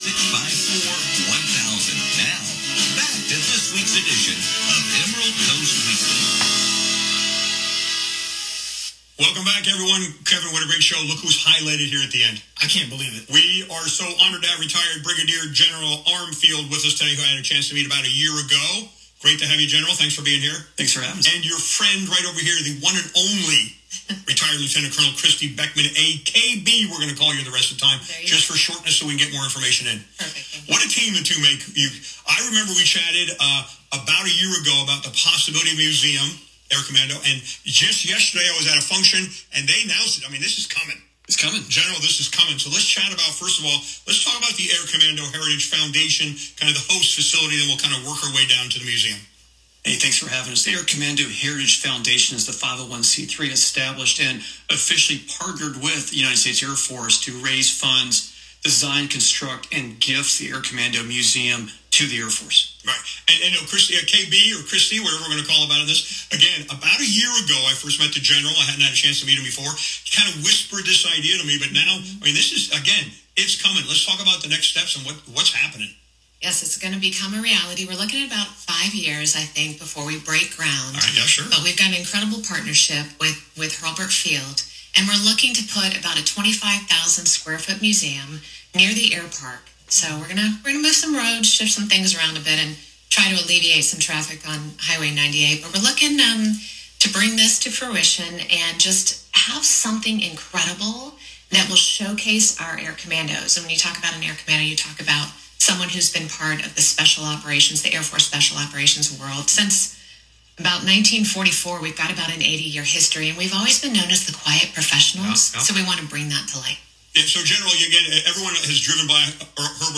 Listen to radio interview: ACHF talks about the Air Commando Museum
In a recent Emerald Coast Weekly radio interview, the spotlight was on the ACHF and their mission to preserve the legacy and valor of America's elite Air Commando's and how the community can get involved and why it matters!